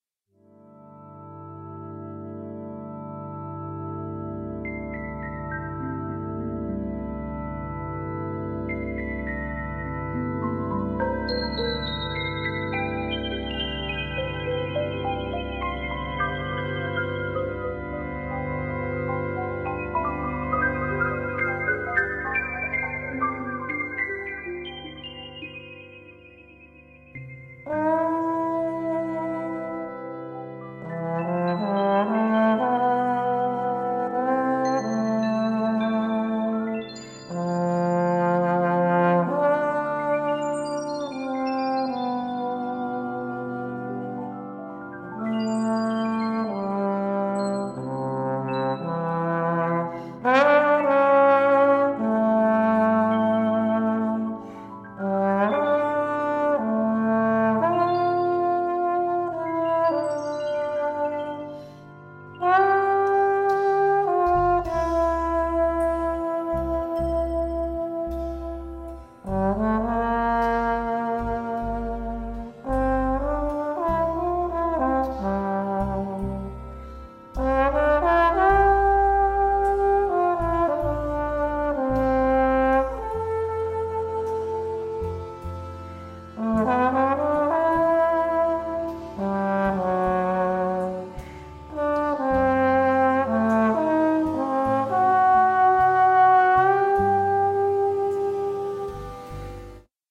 for trombone and electronics